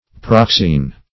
proxene - definition of proxene - synonyms, pronunciation, spelling from Free Dictionary
Search Result for " proxene" : The Collaborative International Dictionary of English v.0.48: Proxene \Prox"ene\, n. [Cf. ?; ? before + ? a guest, stranger: cf. F. prox[`e]ne.]